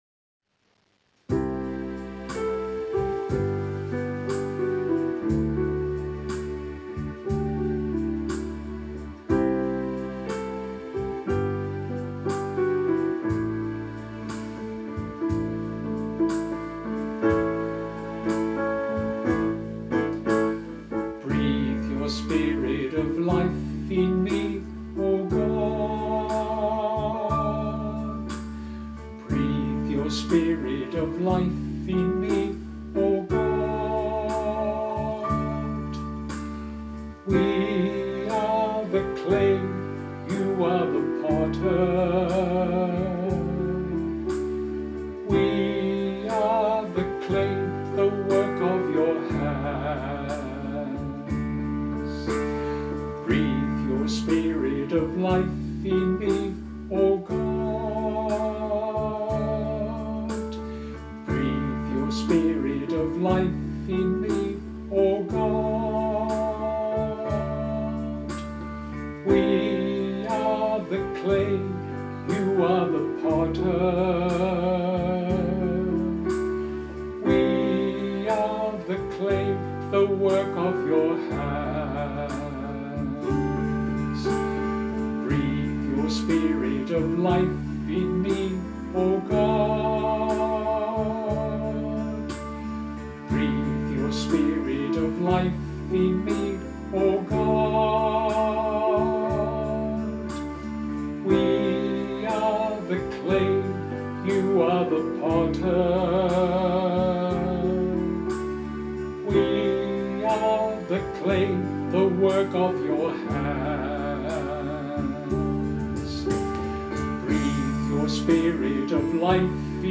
Vocal and Musical Accompaniment